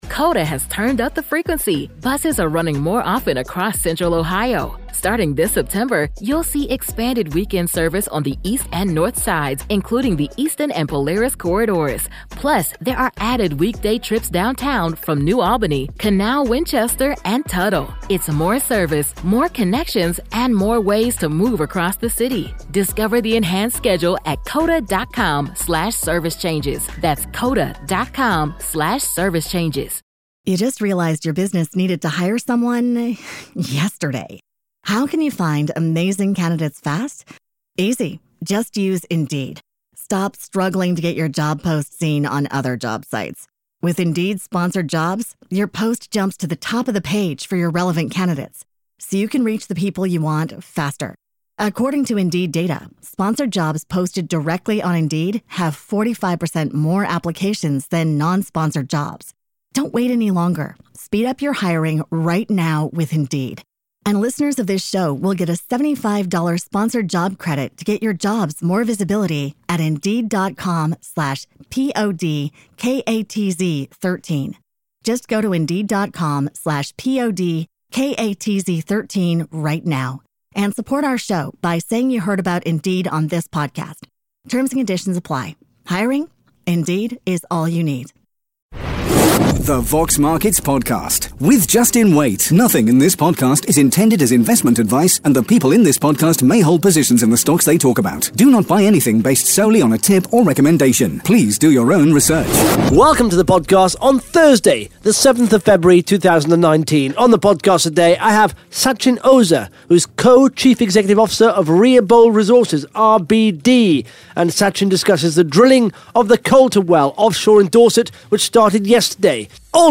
(Interview starts at 12 minutes 5 seconds) Plus the Top 5 Most Followed Companies & the Top 5 Most Liked RNS’s on Vox Markets in the last 24 hours.